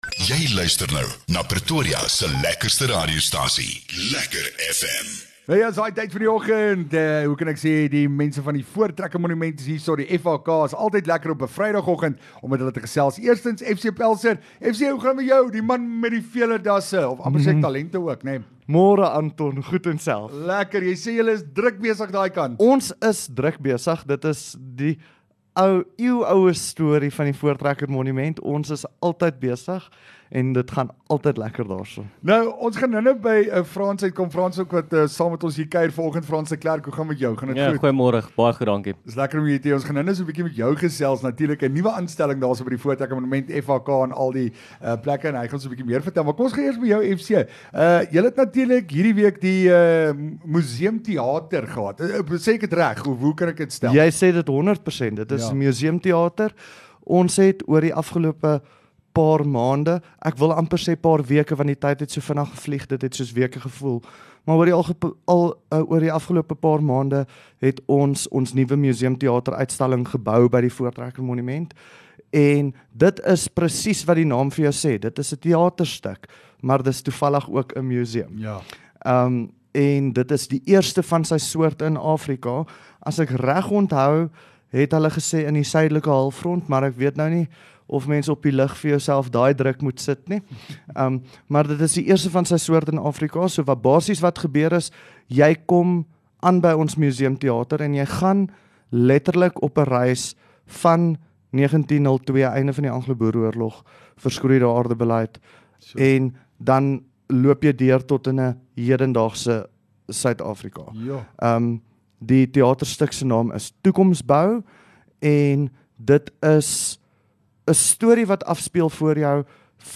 LEKKER FM | Onderhoude 23 Jun Voortrekkermonument